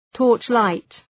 Προφορά
{‘tɔ:rtʃlaıt}
torchlight.mp3